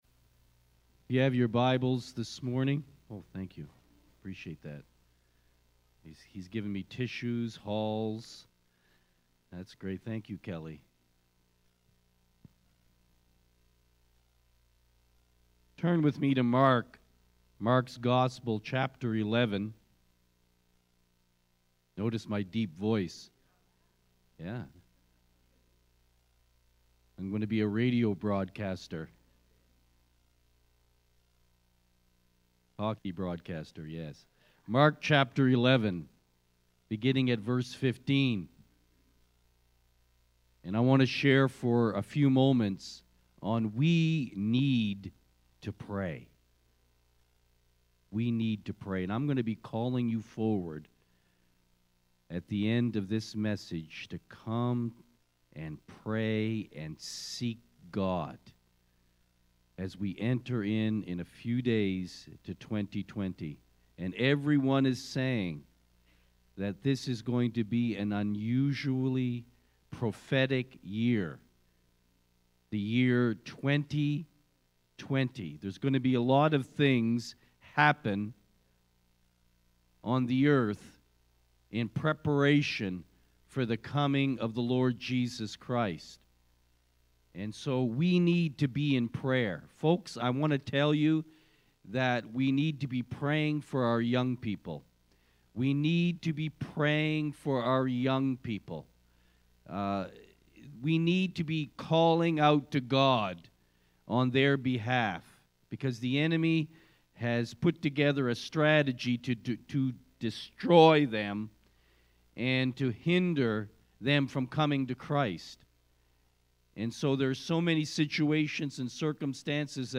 Sermons | Grace Christian Fellowship